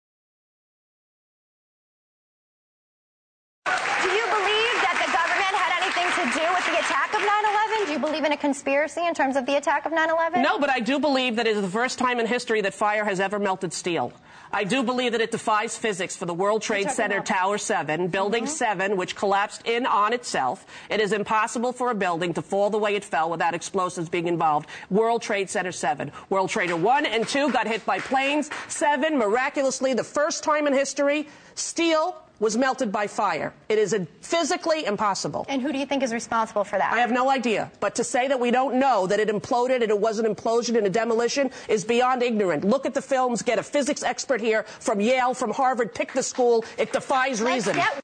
— O’Donnell discussing 9/11 on The View, March 29.